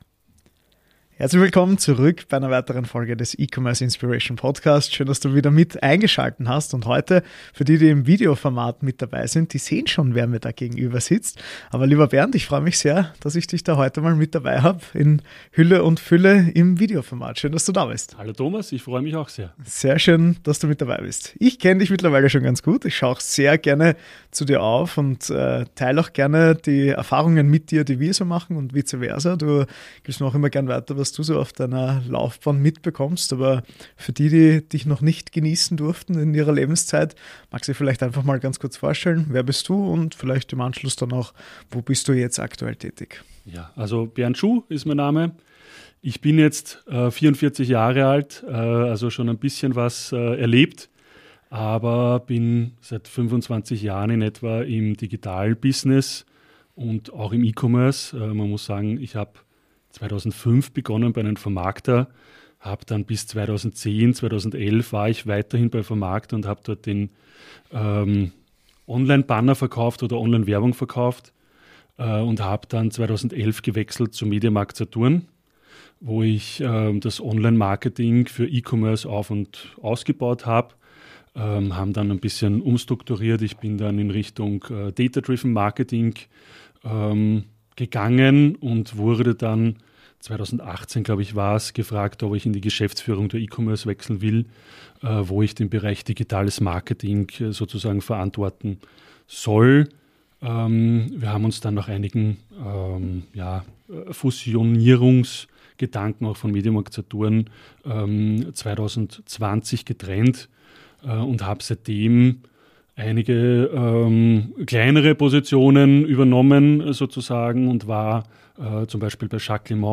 im Interview: Internationalisierung über Marktplätze und Teamführung. ~ E-Commerce Inspiration Podcast: Einblicke in den Onlinehandel